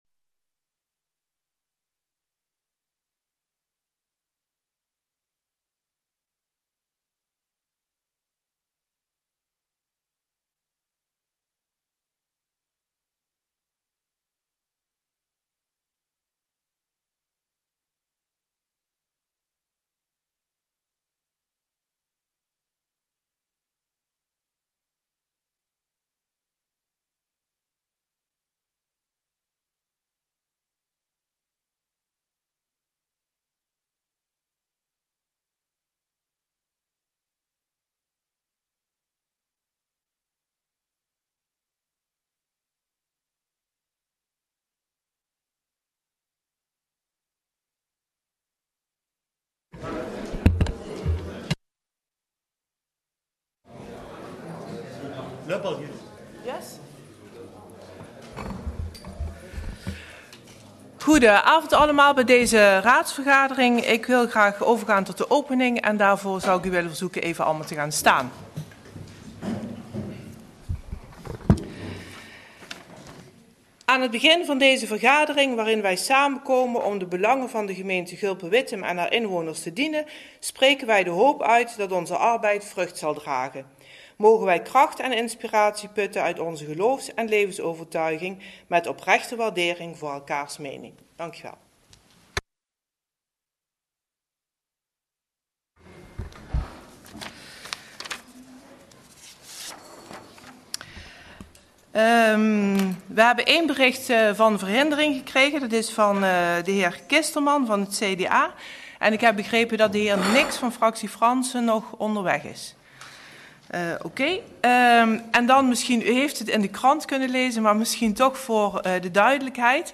Locatie Raadzaal